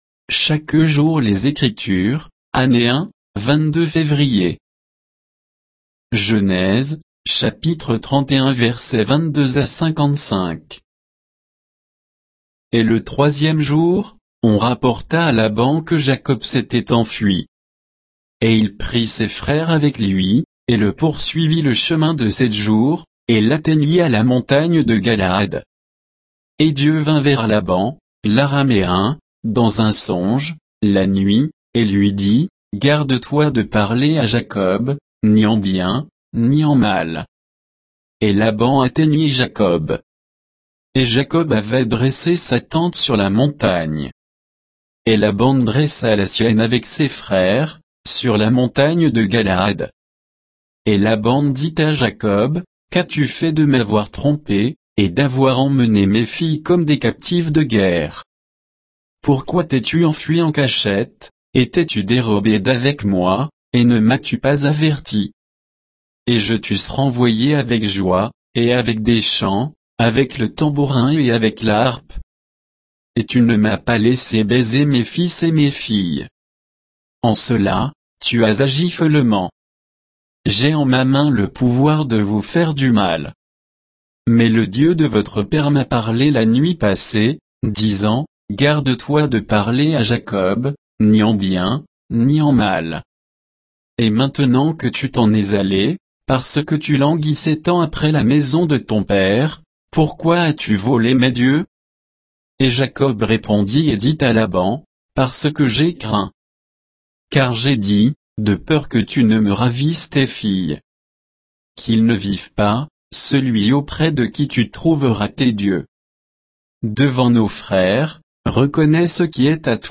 Méditation quoditienne de Chaque jour les Écritures sur Genèse 31